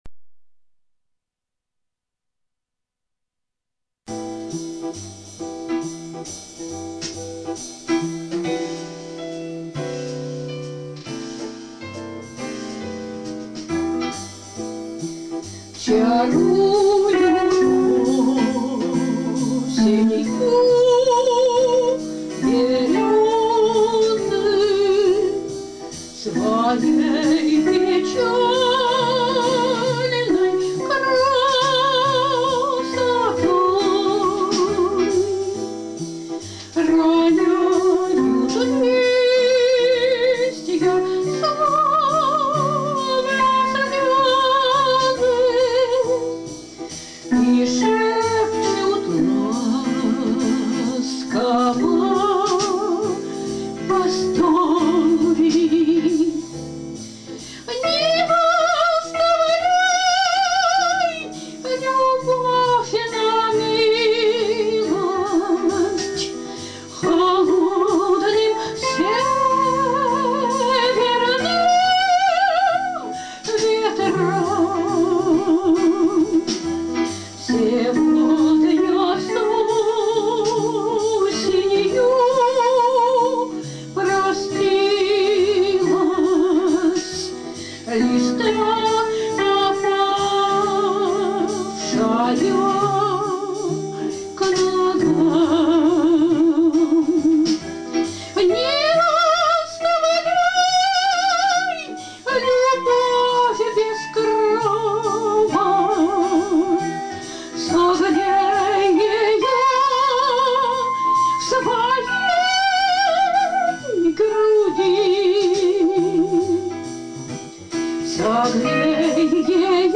Синтезатор
• Жанр: Романс